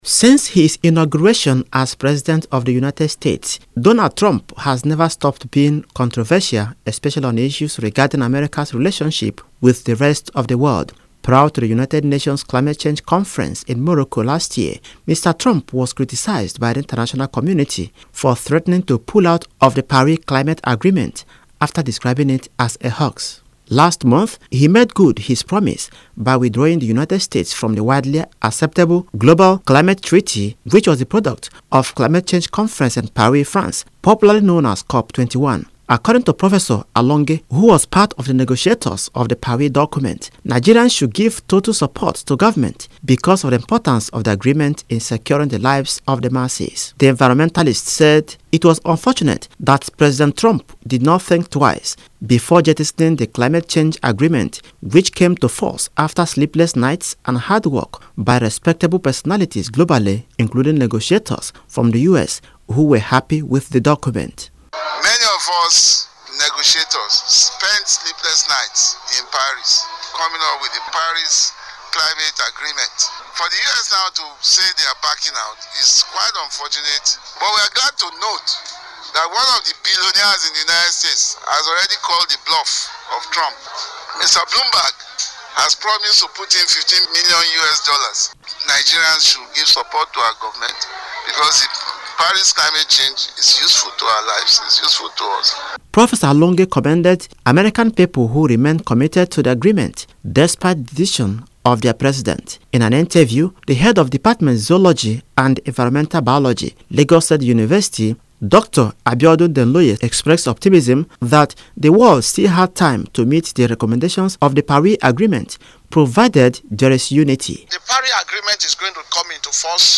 Radio Reports